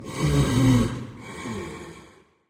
Minecraft / mob / blaze / breathe2.ogg
breathe2.ogg